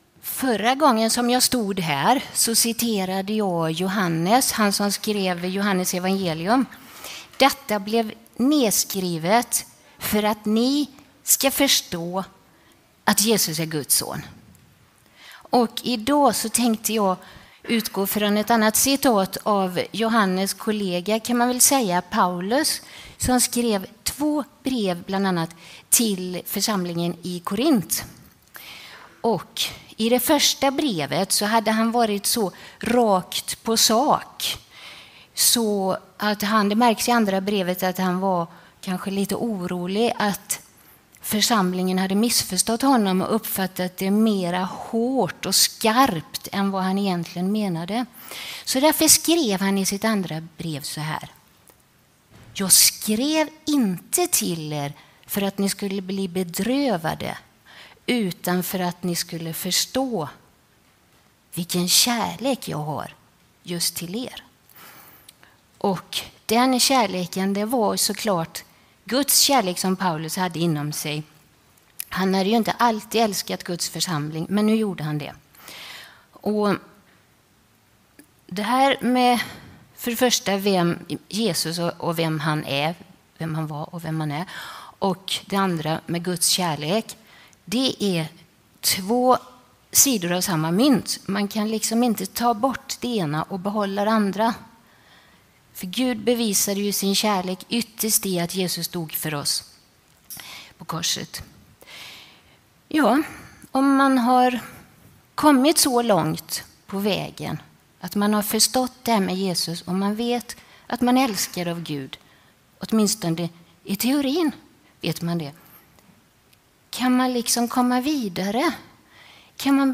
predikar